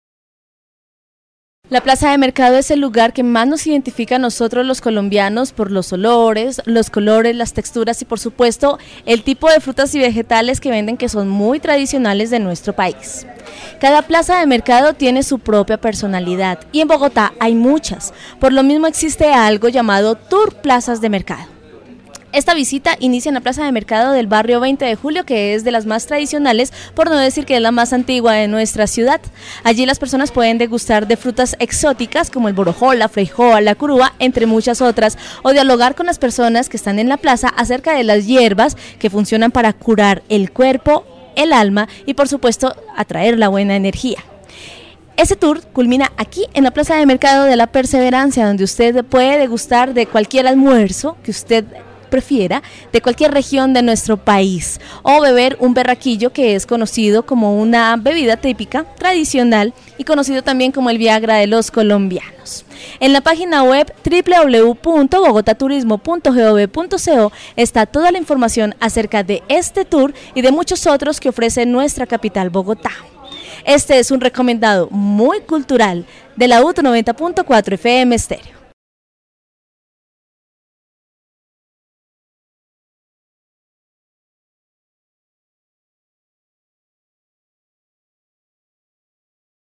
Recomendado 2017. Informe radial: "Plazas de Mercado – Cultura y turismo, 2017"